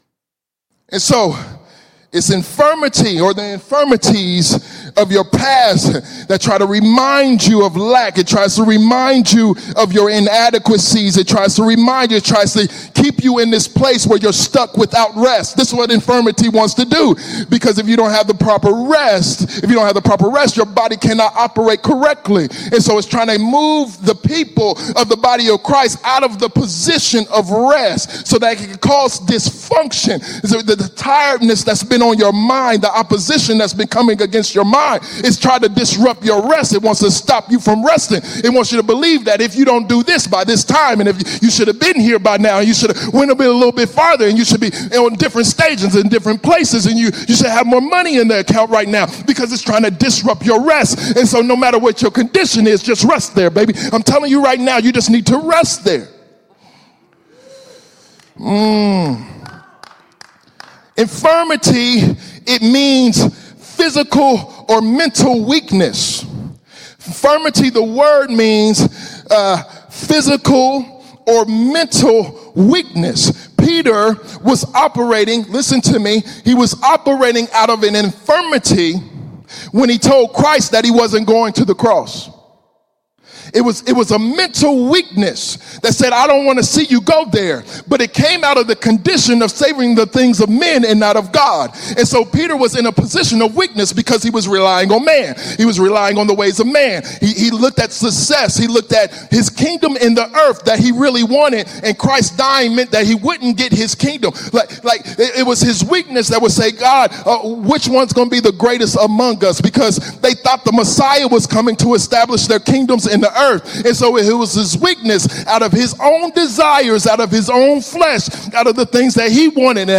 Teachings Tags